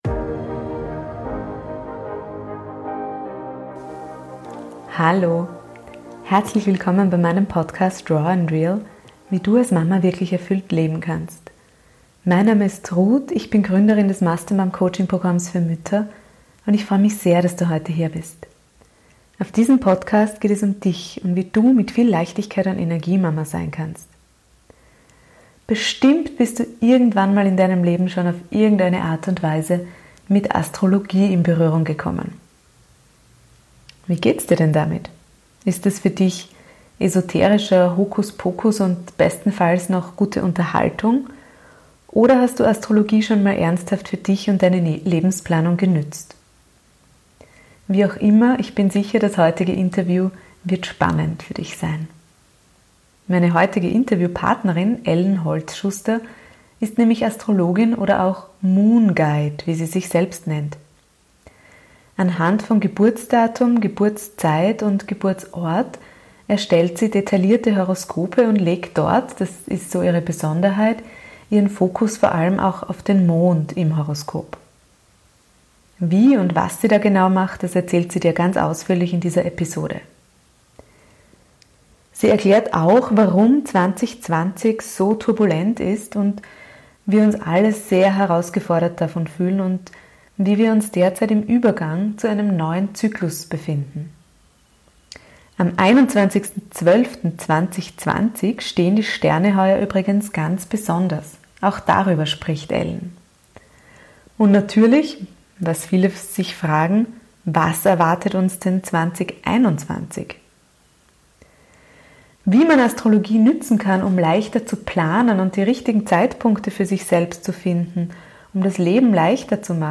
Wie auch immer: das heutige Interview wird ganz sicher spannend für dich sein!